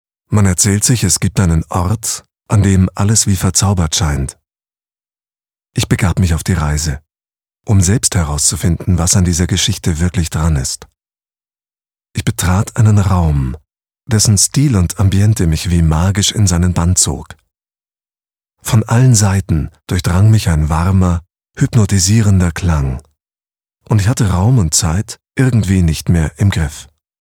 deutscher Sprecher, Moderator und Schauspieler mit bekannter und markanter TV-Stimme. Charakteristik: Tief und warm - jung und wandelbar
Sprechprobe: Werbung (Muttersprache):
german voice over talent, references: Milka, Allianz, Lufthansa, DB u.a.